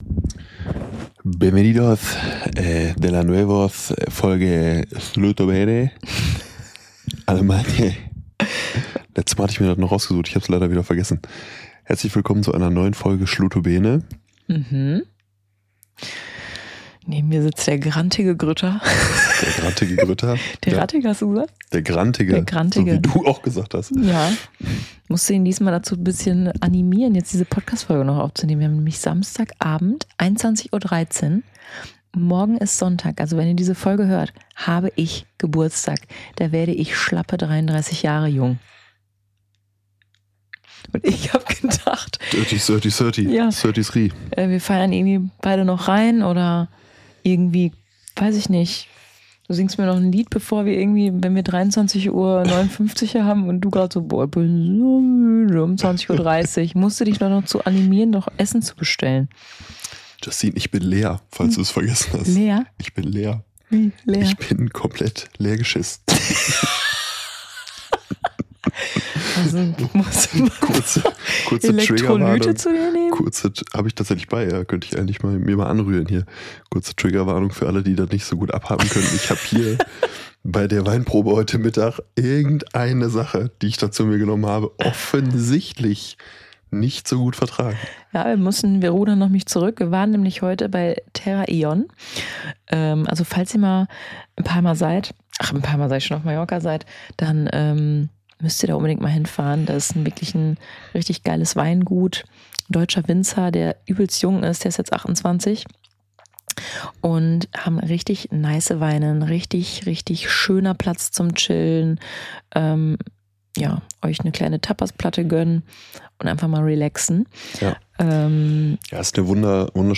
Nach müde kommt dumm und gemäß diesem Motto sprechen die beiden nur noch mit halber Hirn-Leistung.